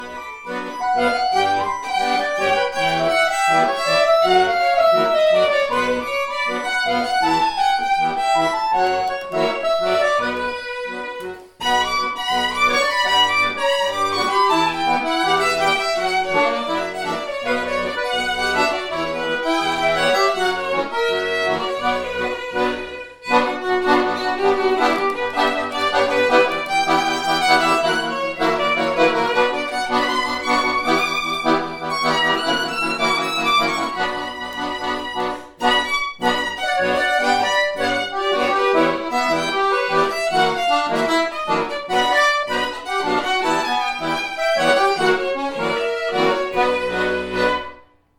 Diese führe sie solo oder mit Band-Besetzung auf.